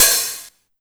VEC3 Cymbals HH Open 002.wav